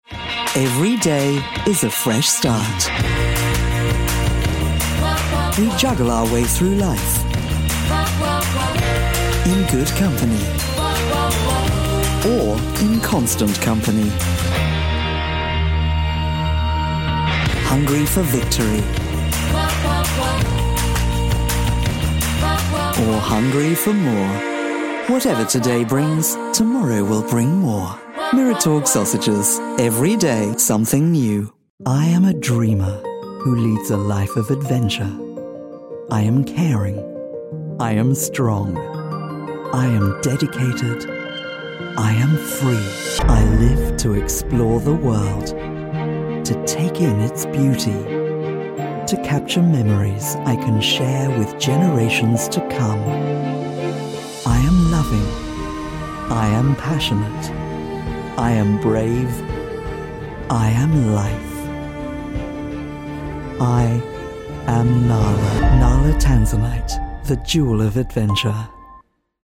20 Second Soundbite
20 Second Soundbite Narration Showreel A supreme narrator and one of the smoothest, most luxurious female voices you’ll hear.
Gender Female Native Accents British RP Neutral
Styles Confident Husky (light) Promo Recognisable Smooth